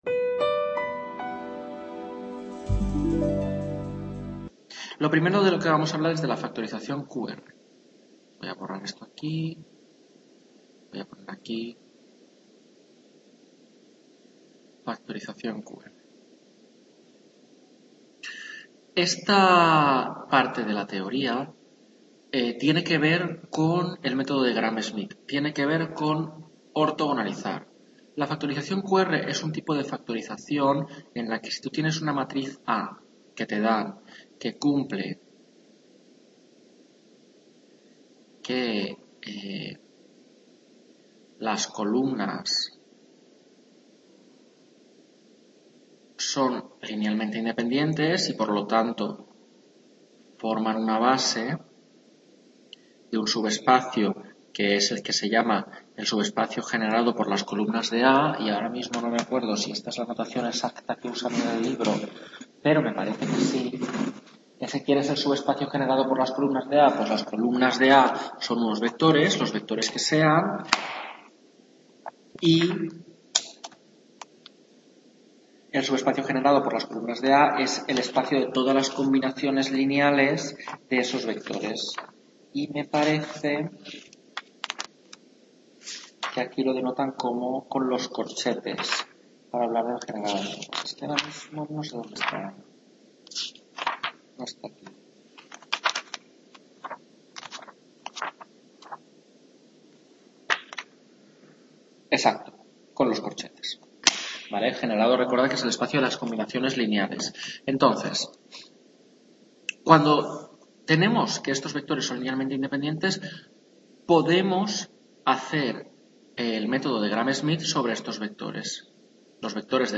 Tutoría 13 Álgebra - Factorización QR, mínimos…